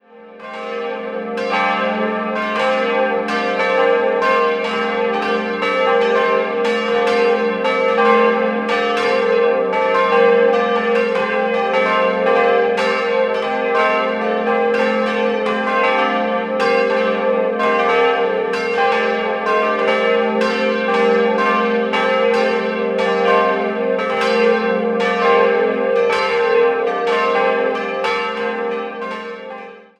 4-stimmiges Geläute: g'-a'-c''-d''